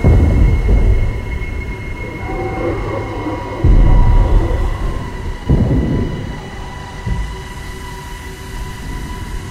Alarm2_4.ogg